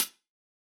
UHH_ElectroHatC_Hit-20.wav